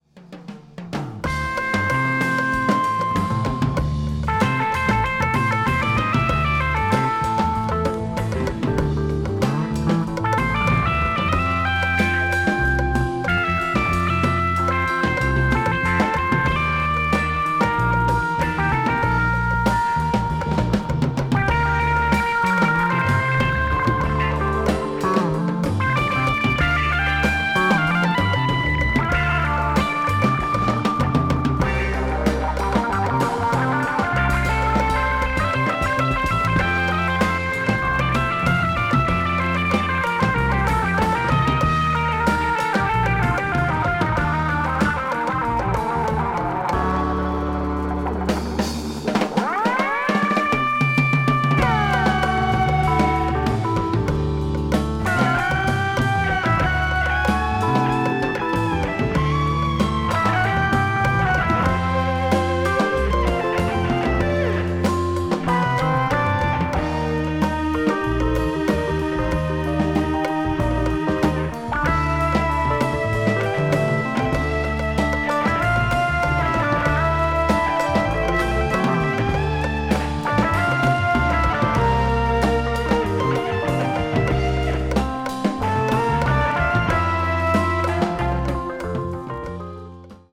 media : VG/VG(light scratch noises and click noises.)
keys, vocals
guitar, vocals